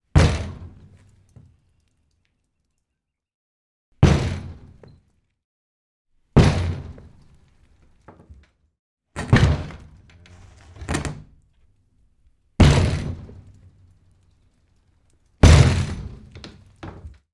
随机的 "木质老式镶嵌玻璃的门，从里面撞击发出响声
描述：门木老与镶嵌玻璃撞击来自inside.wav
Tag: 命中 木材 玻璃 里面 嘎嘎 从老 镶嵌 冲击